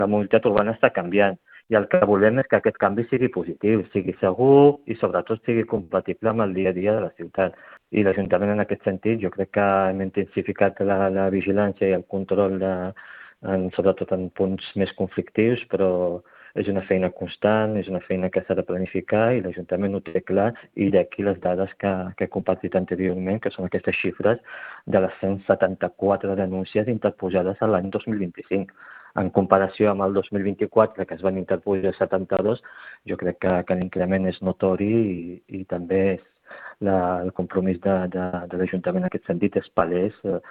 Laroussi assenyala que l’increment de denúncies és una conseqüència directa de la intensificació del control i la vigilància policial per garantir la seguretat viària en un context de canvis en la mobilitat urbana: